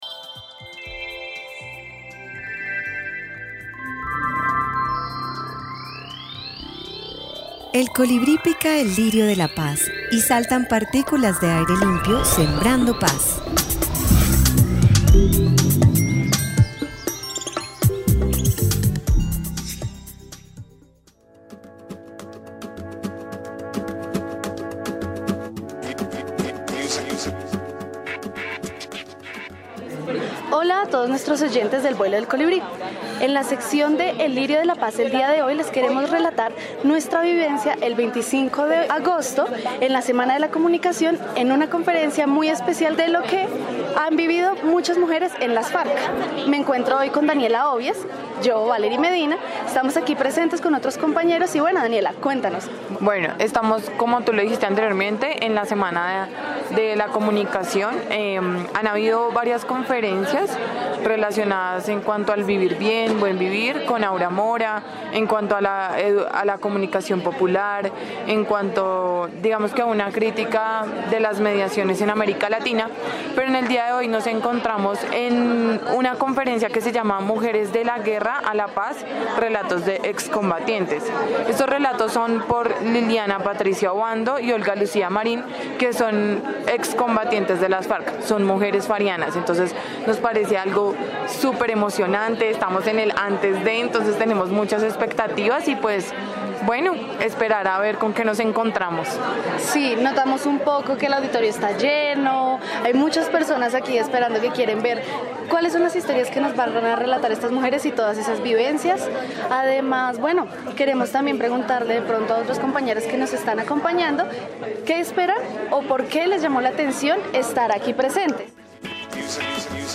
En 2017 se celebró la XI Semana Internacional de la Comunicación en la Corporación Universitaria Minuto de Dios llamada Narrativas Otras Para un Mejor País y El Colibrí con su sección El Lirio de la Paz, realizó una producción radiofónica de la charla Mujeres de la Guerra a la Paz (Relatos de Excombatientes) realizada el 25 de Agosto.